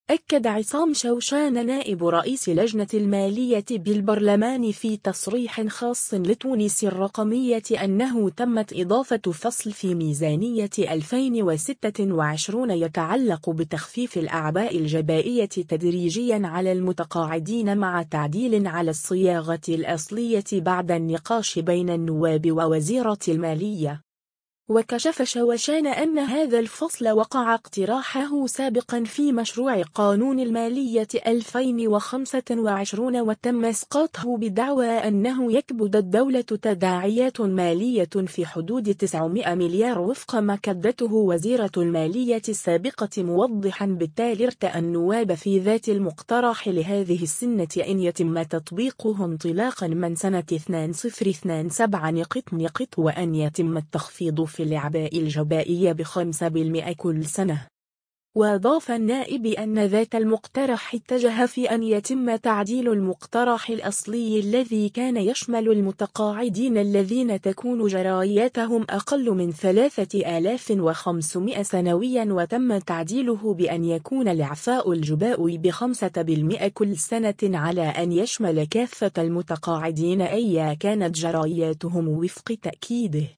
أكد عصام شوشان نائب رئيس لجنة المالية بالبرلمان في تصريح خاص لـ”تونس الرقمية” أنه تمت اضافة فصل في ميزانية 2026 يتعلق بتخفيف الأعباء الجبائية تدريجيا على المتقاعدين مع تعديل على الصياغة الأصلية بعد النقاش بين النواب و وزيرة المالية.